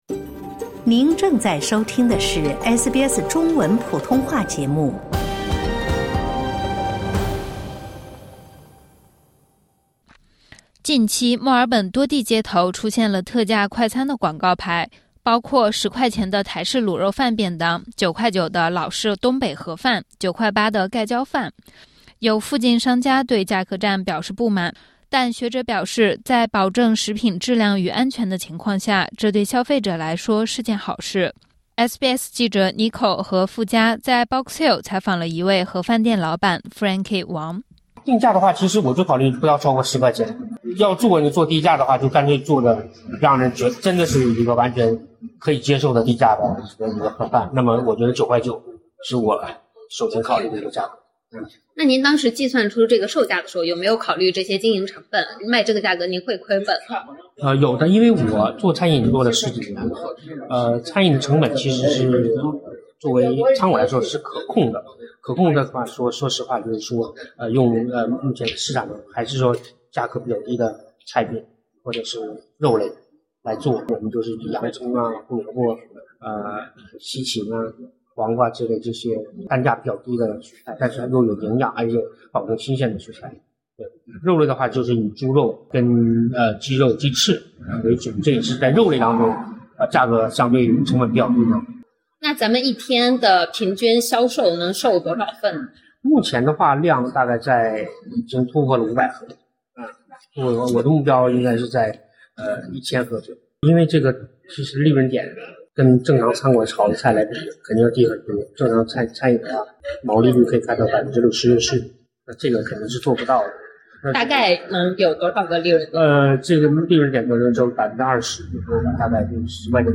SBS普通话针对这一话题采访了商家、消费者和市场学专家。